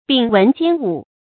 秉文兼武 注音： ㄅㄧㄥˇ ㄨㄣˊ ㄐㄧㄢ ㄨˇ 讀音讀法： 意思解釋： 猶言能文能武。